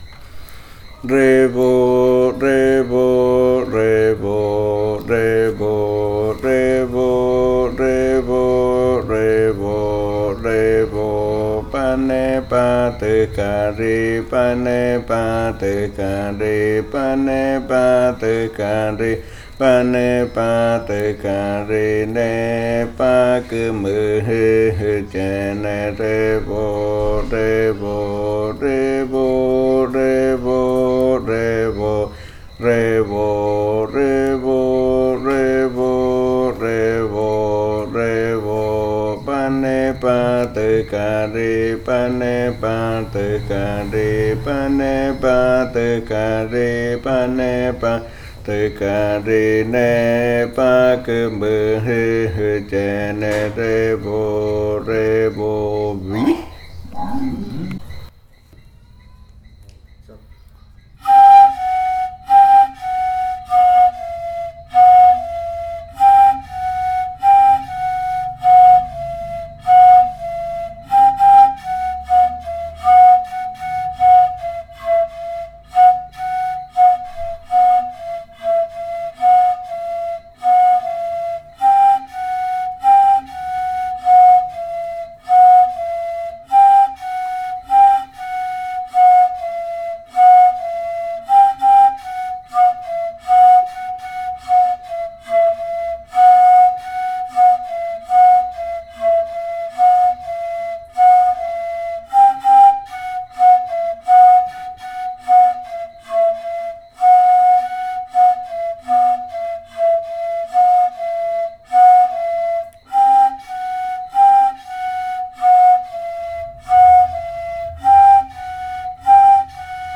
Leticia, Amazonas, (Colombia)
Nééba kúúmujɨ (annato turtle) (Bora language) chant and performance of the chant in pairs of reribakui flutes.
smaller, female flute
larger, male flute
Flautas de Pan y cantos de fakariya del grupo Kaɨ Komuiya Uai